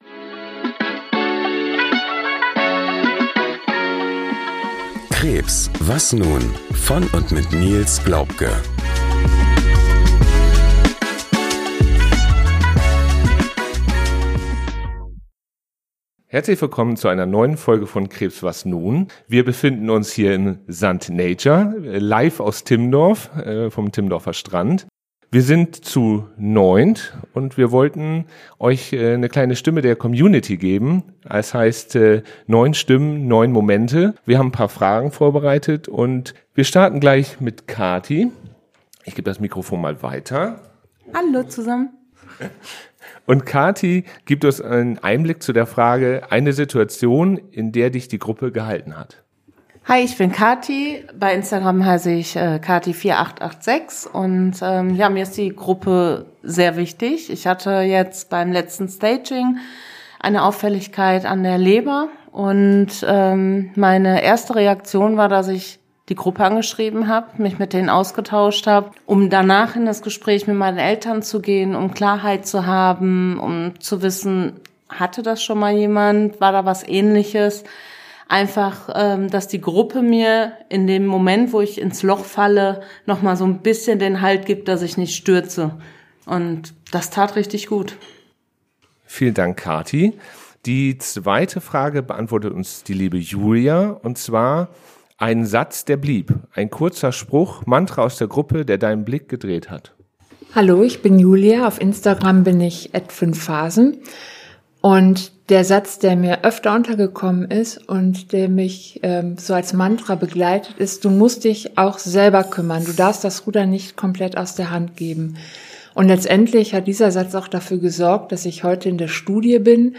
mit nach Timmendorf: Neun Menschen mit Lungenkrebs haben sich zu
einem Mutmacher-Community-Treffen versammelt.